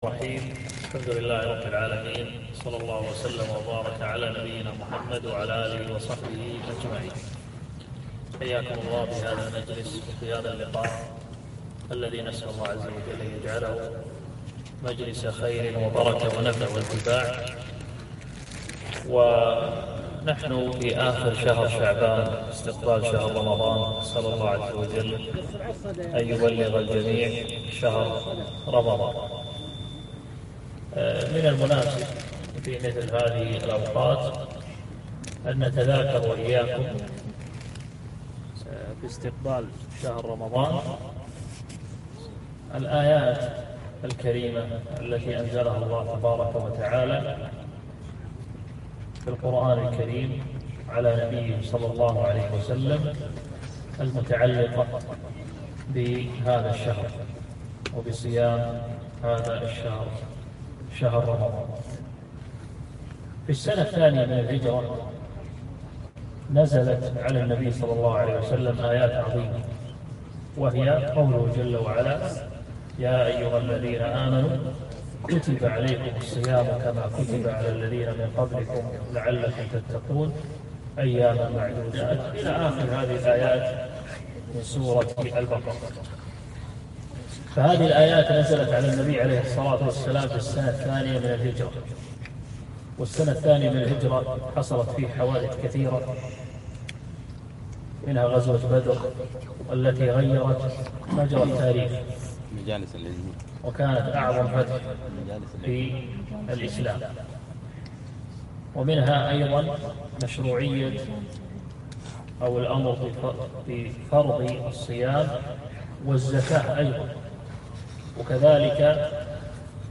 محاضرة - آيات الصيام